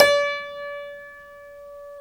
Index of /90_sSampleCDs/Club-50 - Foundations Roland/PNO_xTack Piano/PNO_xTack Pno 1M